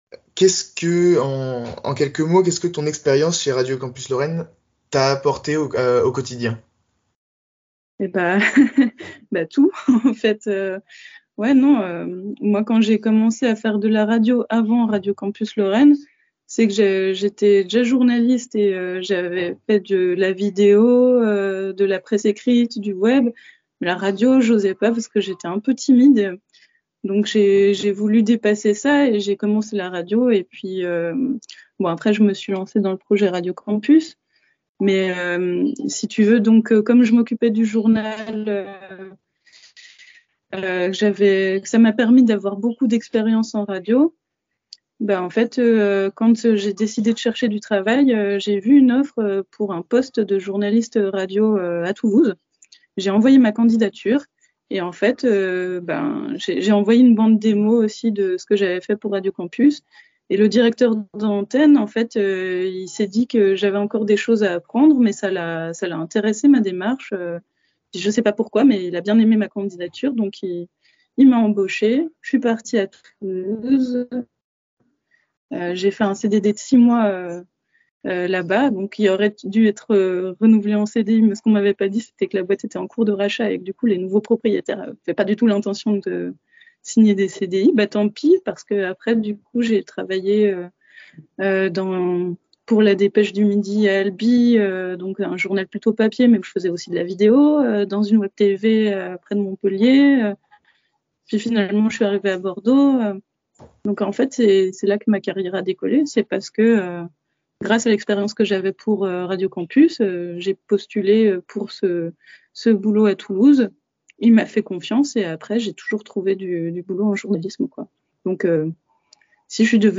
L’INTERVIEW FLASH